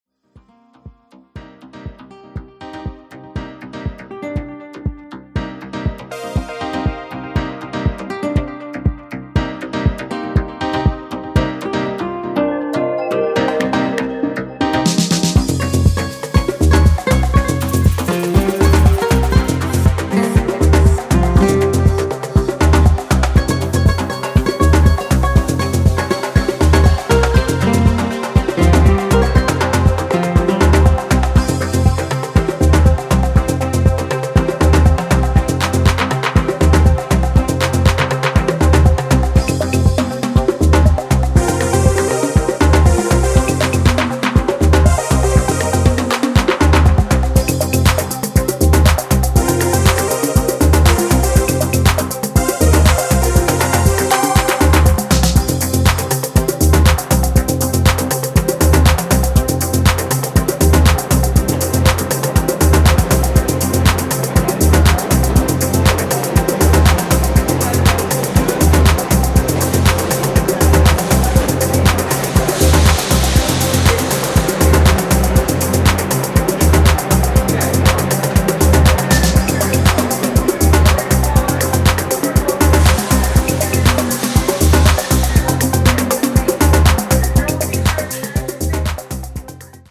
ドリーム・ハウスを彷彿とさせるキラー・バレアリック・ニューディスコ/ハウス！
ジャンル(スタイル) NU DISCO / BALEARIC HOUSE